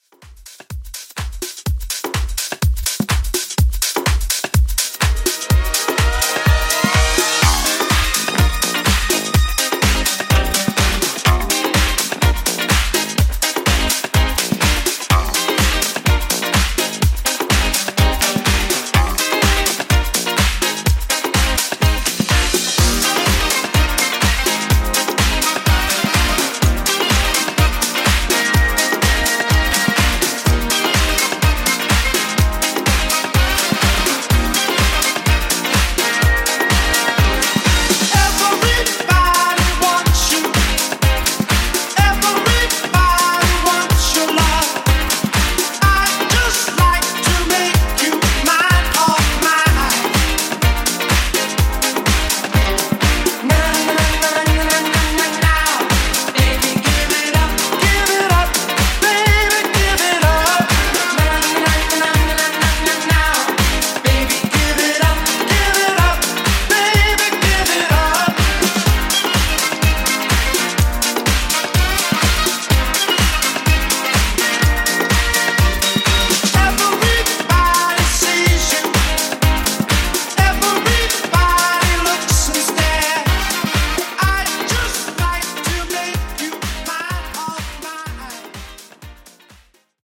80s Club Classic)Date Added